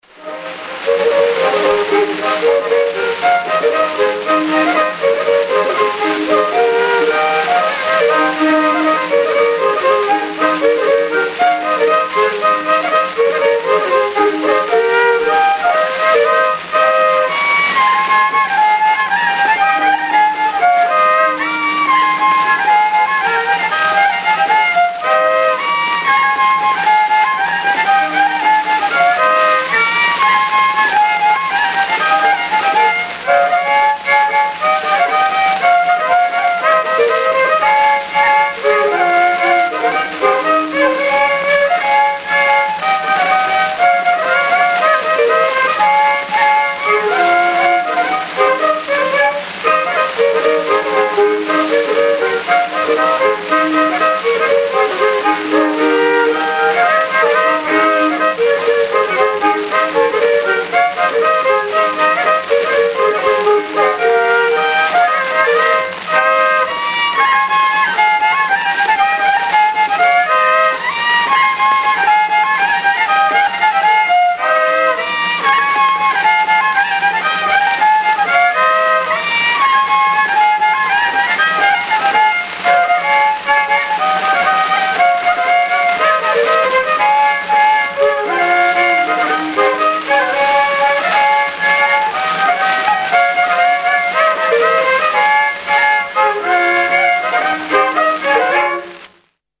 I viiul
II viiul